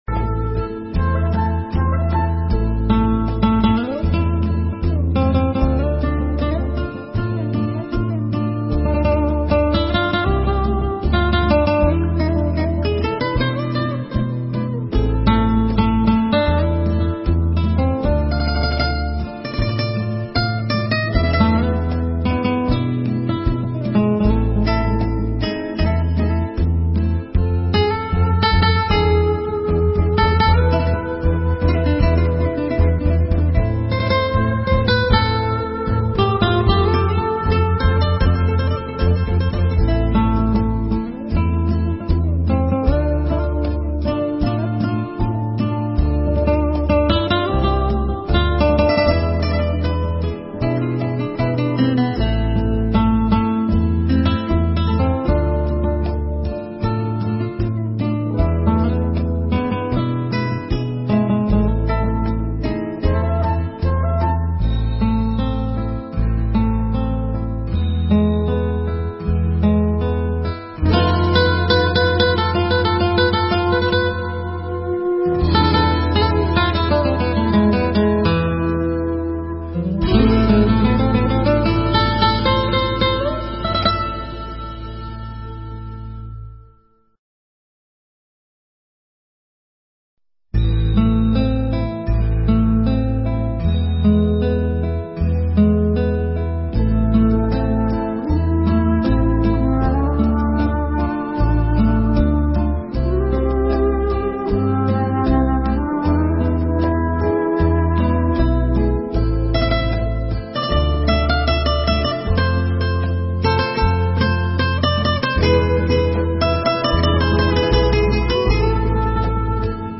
thuyết giảng tại Chùa Hải Đức, Regina, Canada